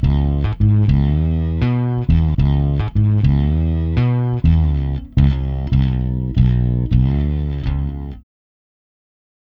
Ala Brzl 1 Bass-C#.wav